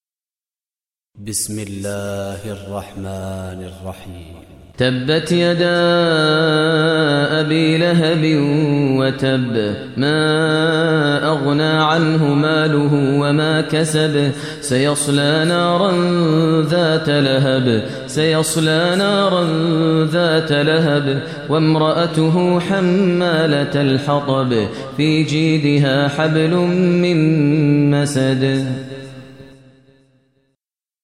Surah Masad Recitation by Maher Mueaqly
Surah Masad, listen online mp3 tilawat / recitation in Arabic recited by Imam e Kaaba Sheikh Maher al Mueaqly.